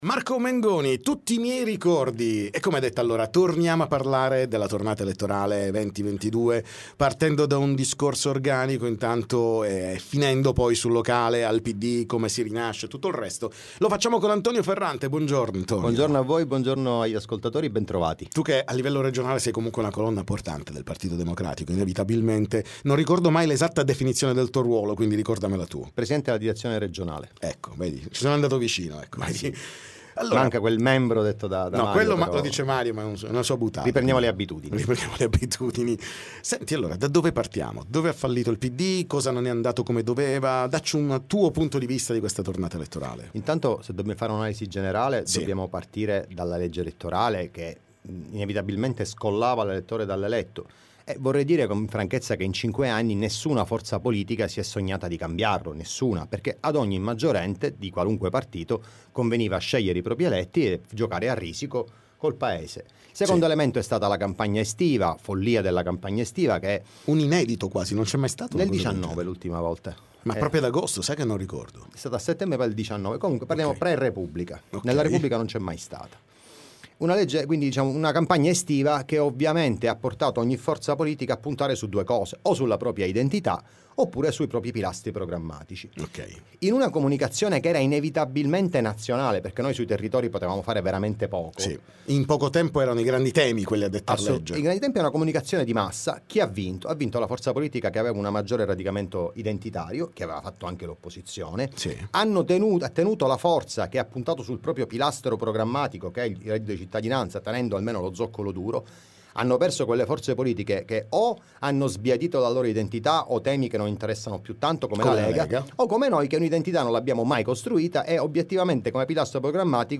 TM Intervista